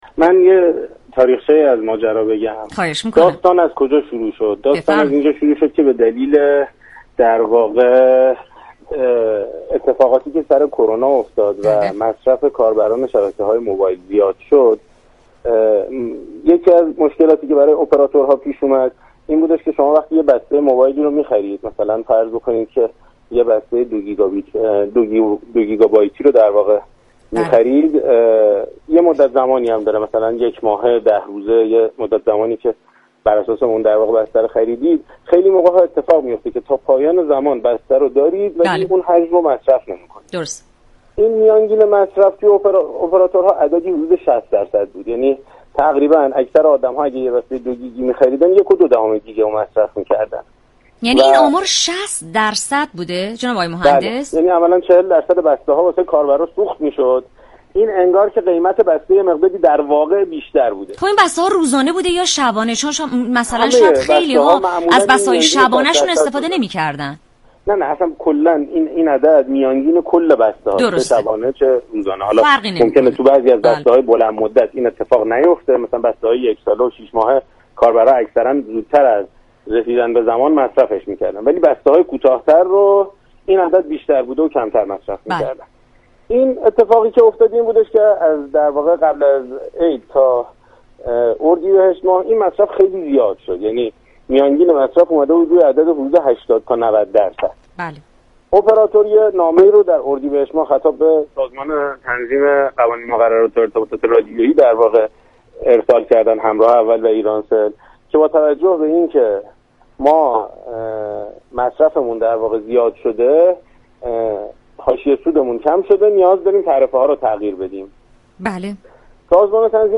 از همین رو دال رادیو جوان در گفتگو با معاون وزیر ارتباطات نحوۀ تعرفۀ این بسته و قیمت‌ها را جویا شد.